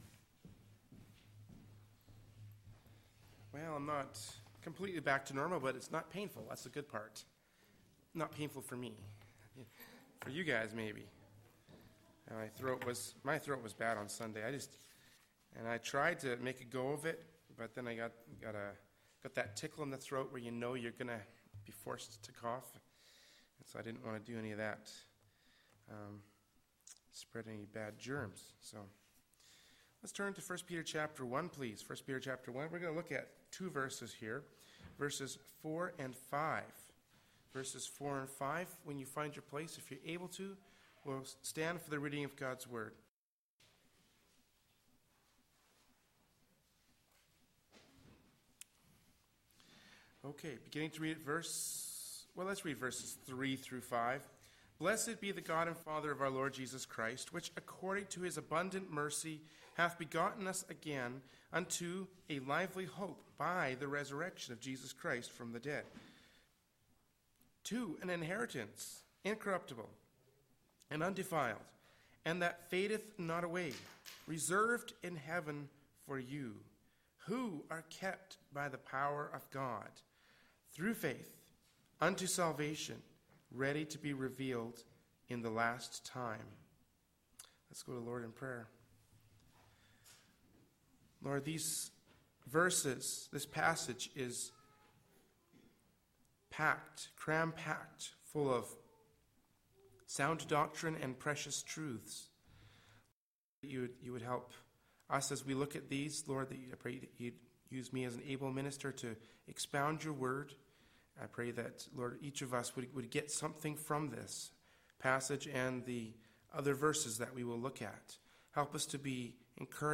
Prayer Meeting